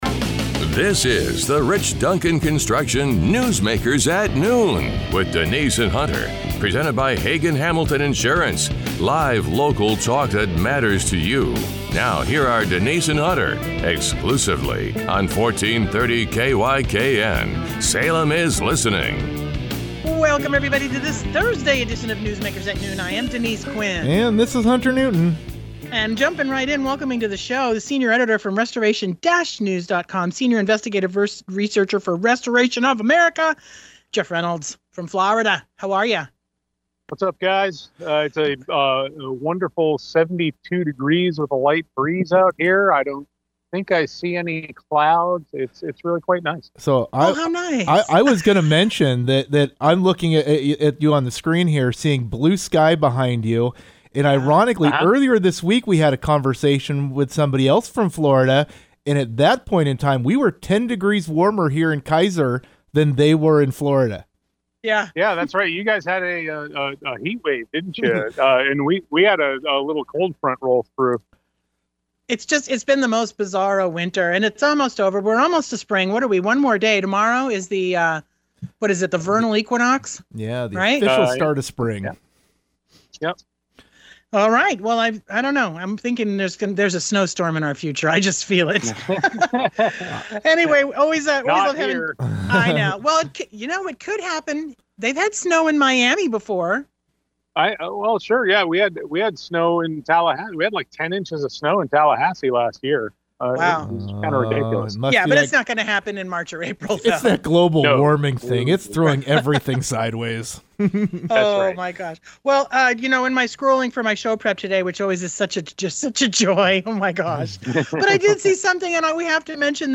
🔥 Today’s show was spicy, passionate, and unapologetic—tackling the biggest headlines with zero hesitation: